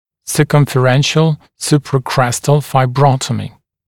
[səˌkʌmfə’renʃl ˌsuprə’krestl faɪbə’rɔtəmɪ][сэˌкамфэ’рэншл ˌсупрэ’крэстл файбэ’ротэми]циркулярная фибротомия, надсечение круговой связки зуба